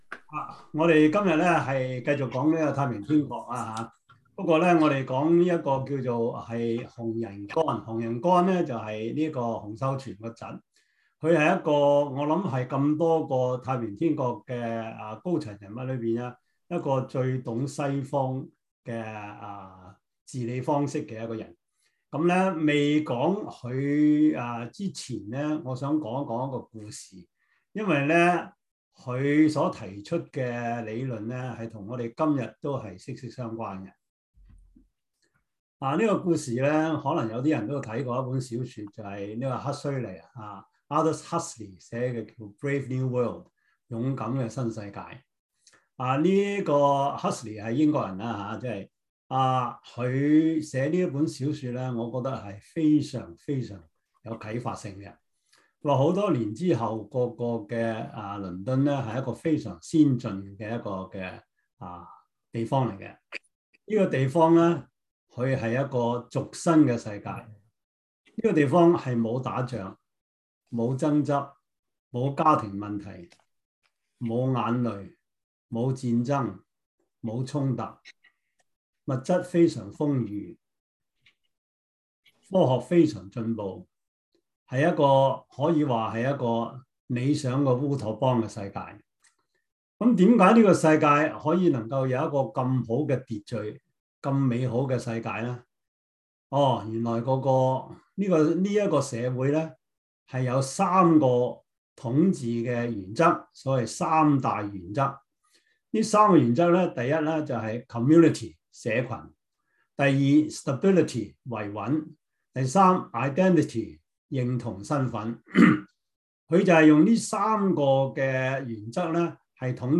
教會歷史 Service Type: 中文主日學 Preacher